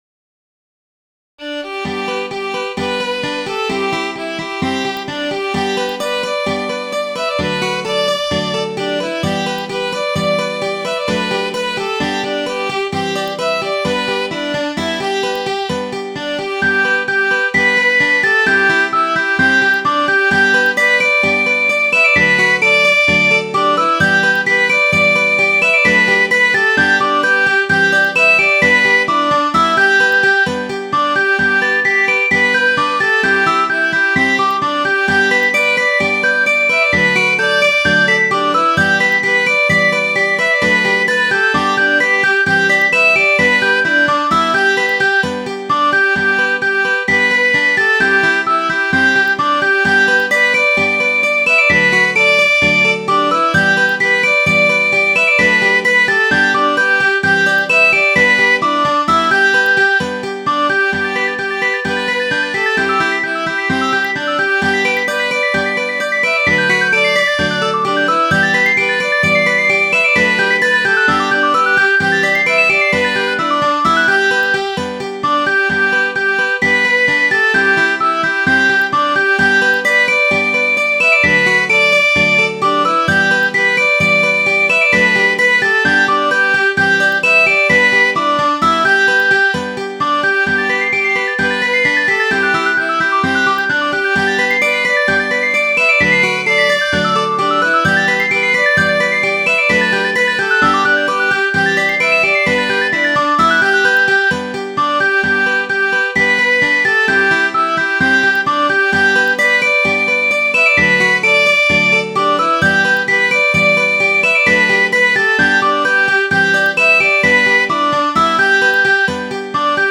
Midi File, Lyrics and Information to The Gypsy Laddie